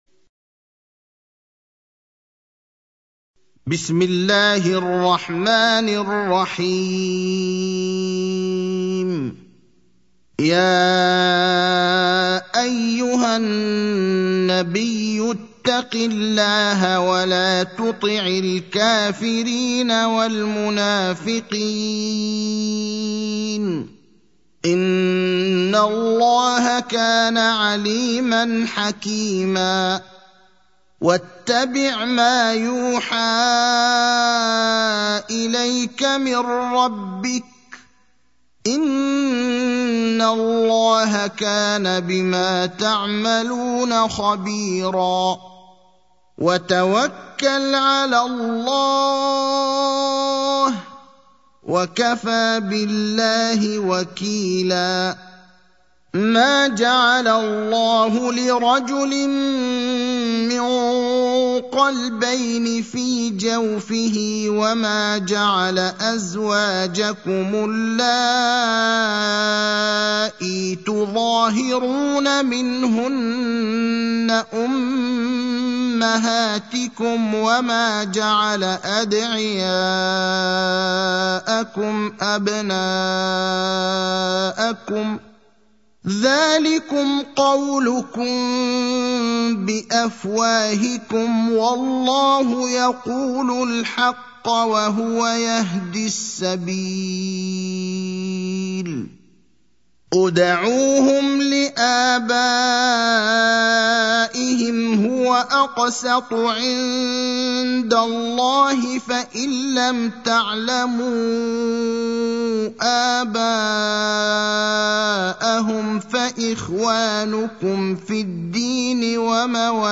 المكان: المسجد النبوي الشيخ: فضيلة الشيخ إبراهيم الأخضر فضيلة الشيخ إبراهيم الأخضر الأحزاب (33)